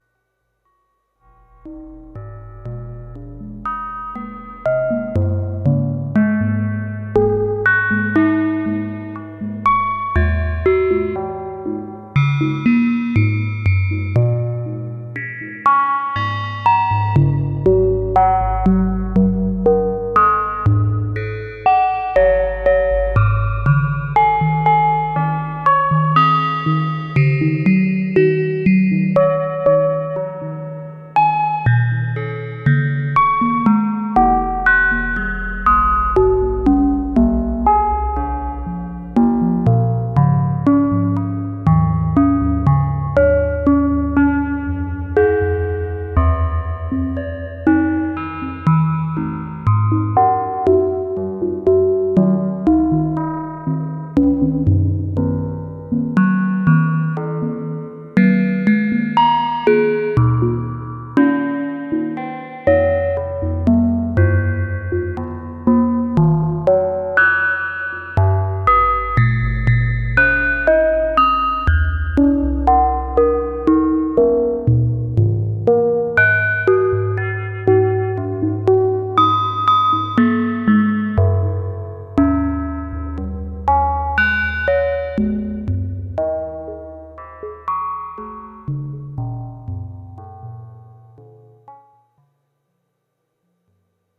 Modular étude. Four random minor pentatonic sequences from Clank Chaos, one pinging the VCFQ filter fed into the Sarajewo BBD delay (low), and three into the oscillators of Three Body, tuned an octave apart in free mode, with modest FM and PM cross-modulation dialed in. Mixed and fed through the Desmodus Versio for reverb.